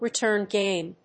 アクセントretúrn mátch [gáme]